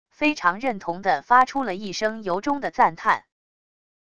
非常认同地发出了一声由衷的赞叹wav音频